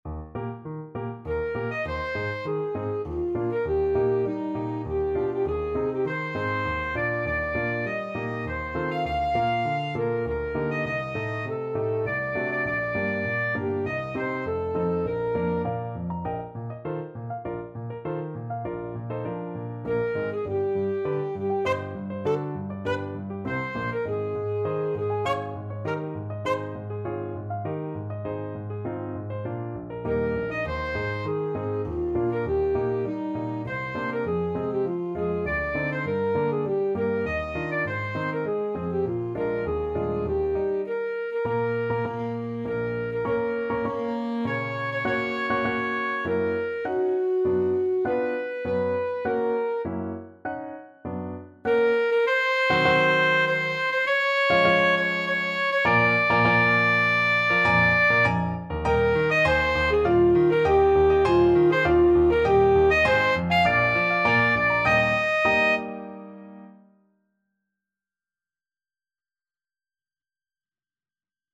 Classical Brahms, Johannes Kleine Hochzeits-Kantate, WoO 16 Alto Saxophone version
3/4 (View more 3/4 Music)
Eb major (Sounding Pitch) C major (Alto Saxophone in Eb) (View more Eb major Music for Saxophone )
~ = 100 Tempo di Menuetto
Classical (View more Classical Saxophone Music)